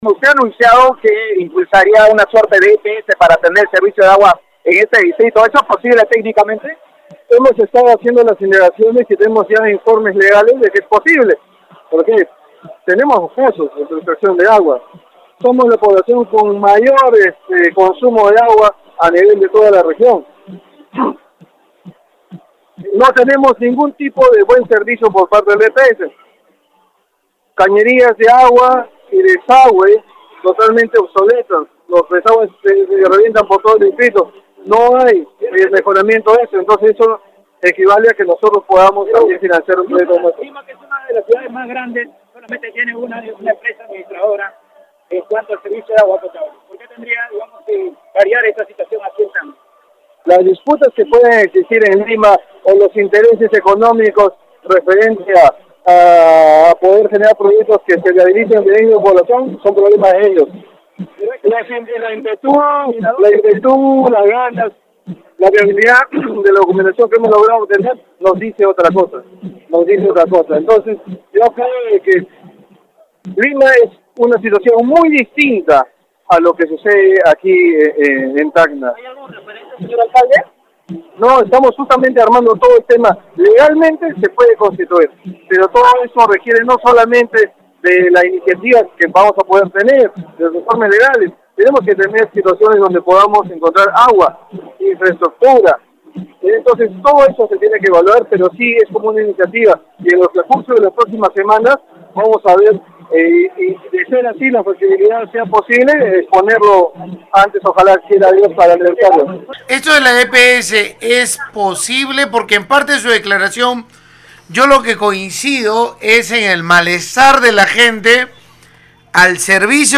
Niel Zavala, alcalde del distrito Gregorio Albarracín, destacó al término de la conferencia de aniversario de su jurisdicción, que es factible tener una Empresa Prestadora de Servicios (EPS) propia en el cono sur de la ciudad, desprendiéndose de la actual por «incapacidad en atención al usuario».